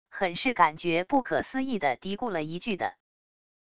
很是感觉不可思议的嘀咕了一句的wav音频